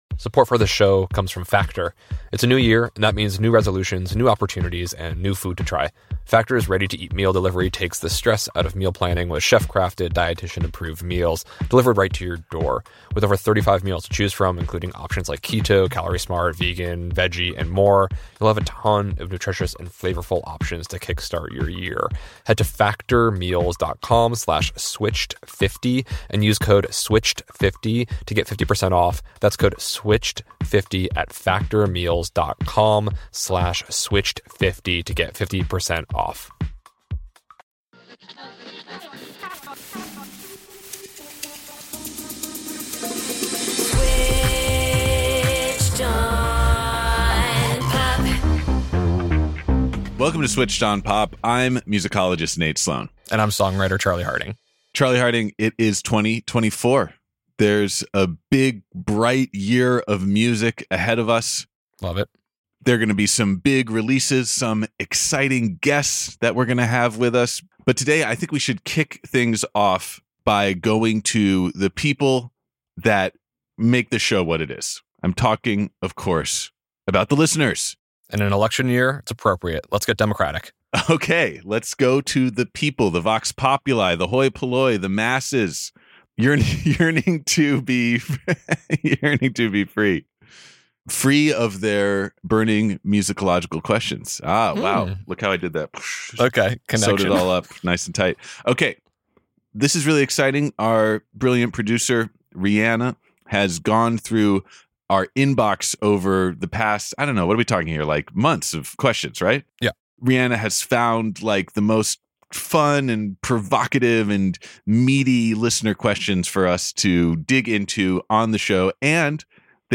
live on air!